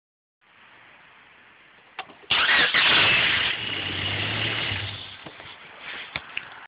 Hi bin seit kurzen auch EJ6 Fahrer und habe nun das Problem, dass wenn ich den Motor zünde, man ein leichtes Knacken warnehmen kann.
Wenn der Motor erst einmal läuft hört man nix mehr, nur kurz beim Anlassen.
also habe jetzt ein Soundfile hochgeladen, nur wie ich schon sagte hört man eigentlich nur den Motor starten.